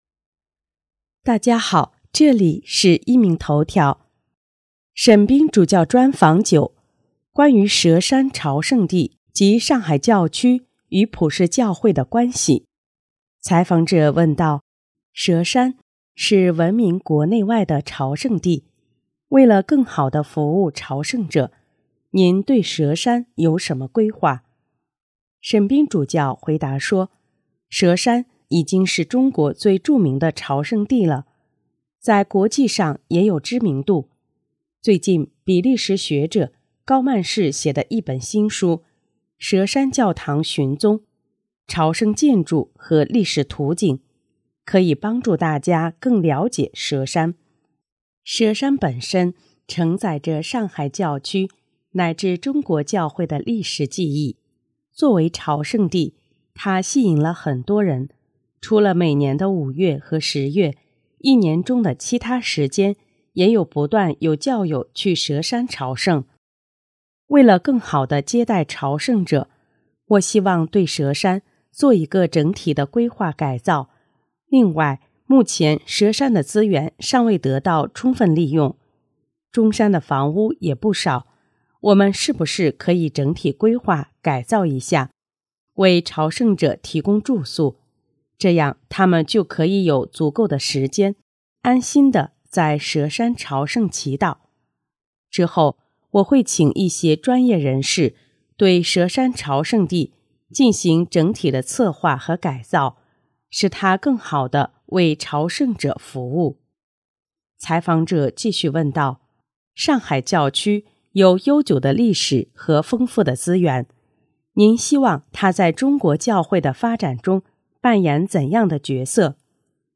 【壹明头条】|沈斌主教专访(九)：关于佘山圣地及上海教区与普世教会的关系